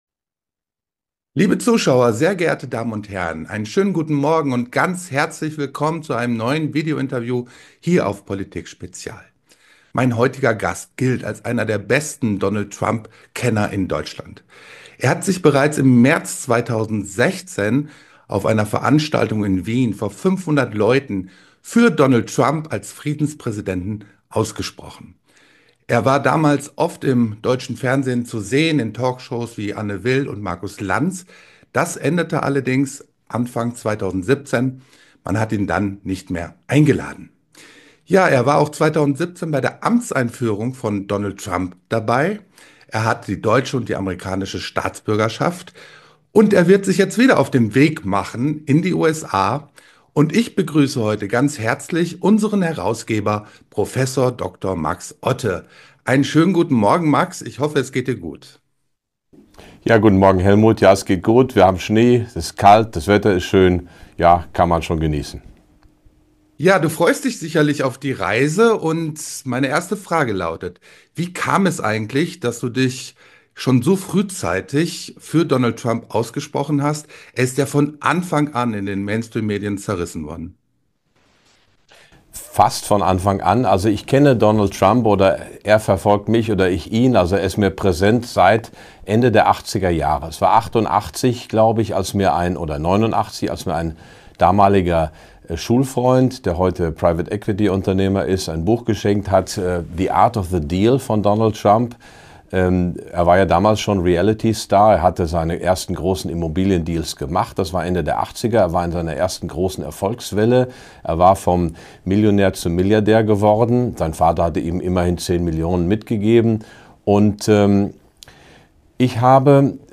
Prof. Dr. Max Otte wird in Washington vor Ort sein.